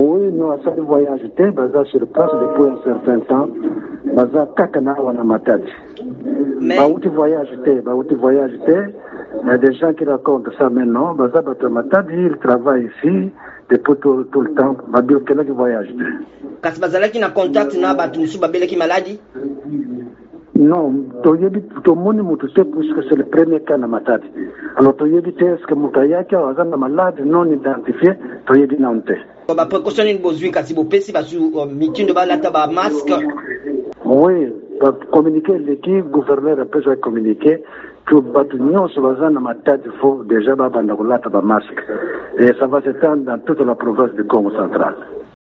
Bakonzi ya Kongo central basakoli ete bolati masque bokomi lotomo na etuka eye nsima na bato babale ba yambo bazwami na COVID-19 na Matadi. VOA Lingala ebngaki na singa minsitre provincial ya Bokolongono, Nestor Mandiangu Makita.